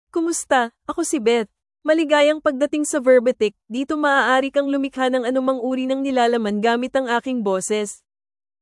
BethFemale Filipino AI voice
Beth is a female AI voice for Filipino (Philippines).
Voice sample
Listen to Beth's female Filipino voice.
Female
Beth delivers clear pronunciation with authentic Philippines Filipino intonation, making your content sound professionally produced.